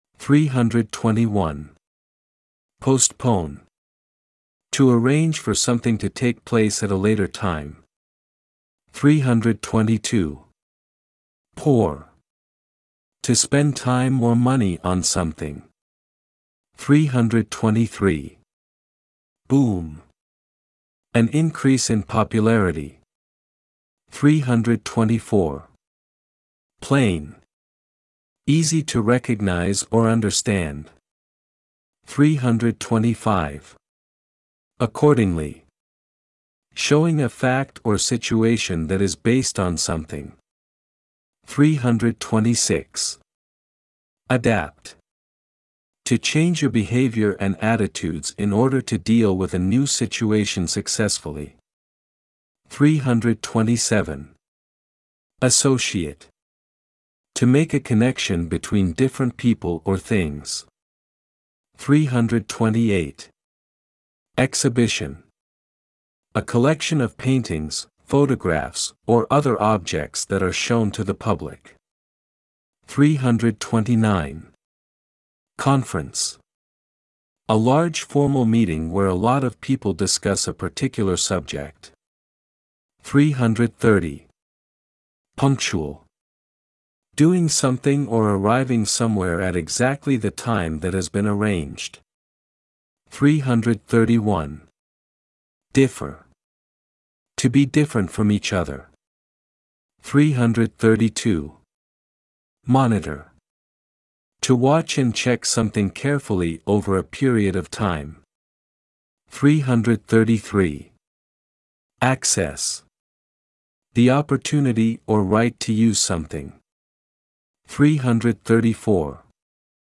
45日目(541)~(620) ※ (541)~(620)単語・英英定義・例文音声 ※英単語を転記し選んだ訳語を記入してください。